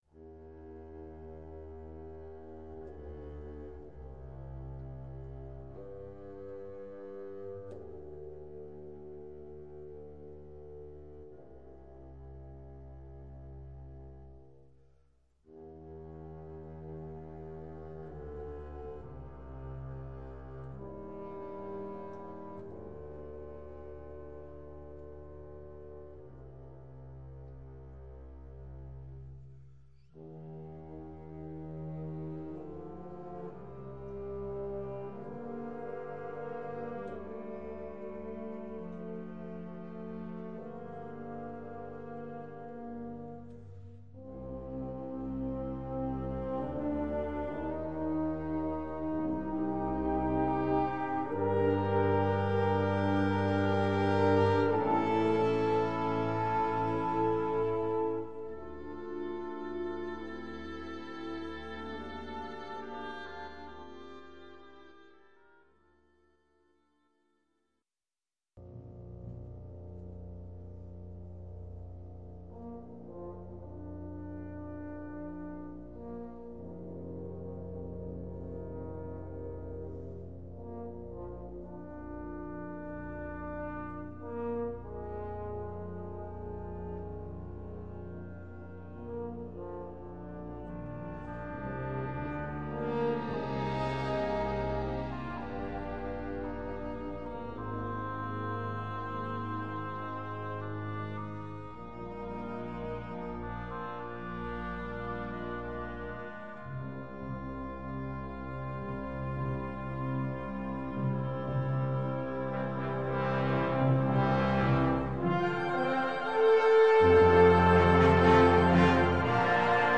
9:05 Minuten Besetzung: Blasorchester Zu hören auf